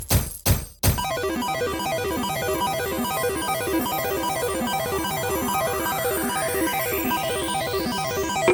djekpot-de.mp3